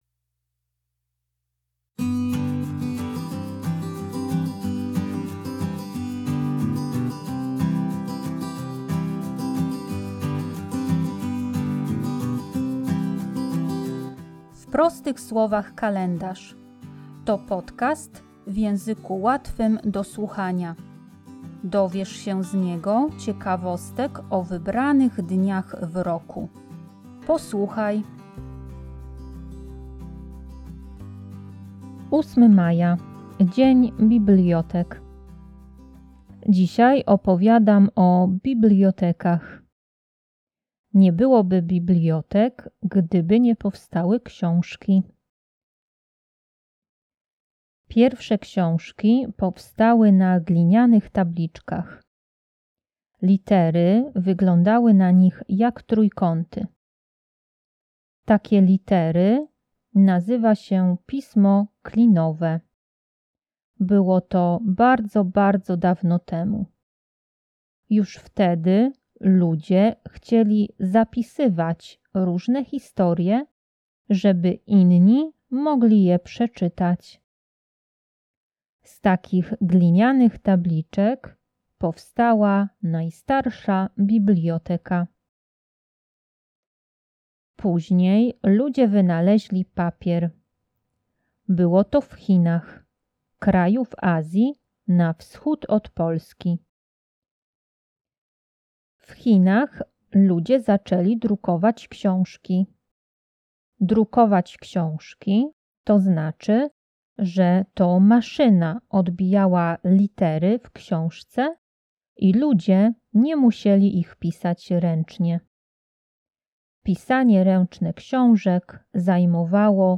Podcast w języku łatwym do słuchania - odcinek 28